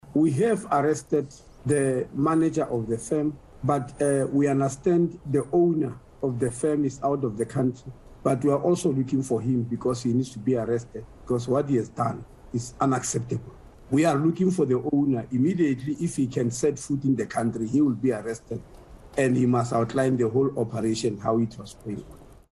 He spoke to eNCA and says the victims were rescued after a tip-off from members of the public.